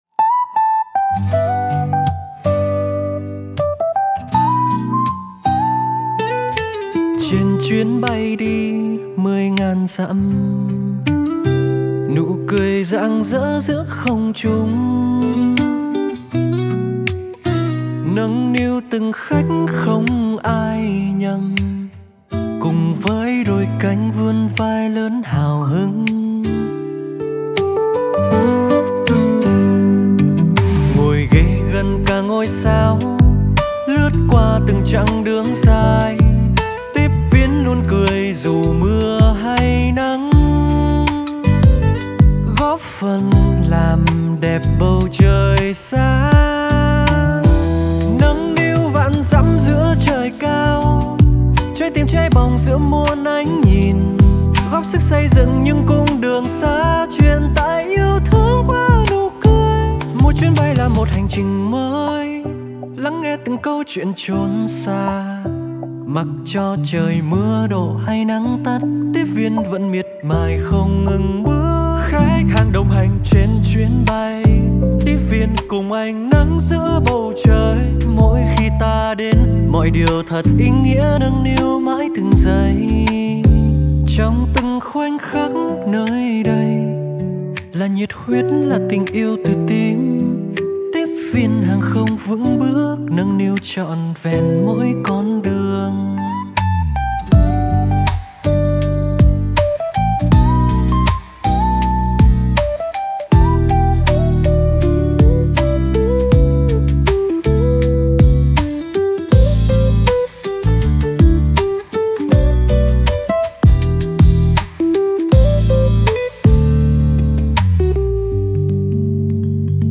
Lấy cảm hứng từ những chuyến bay dài và sự tận tâm phục vụ hành khách, ca khúc mang giai điệu bay bổng, trữ tình, phản ánh cam kết của tiếp viên hàng không Vietnam Airlines trong việc mang đến chất lượng dịch vụ tốt nhất cho hành khách.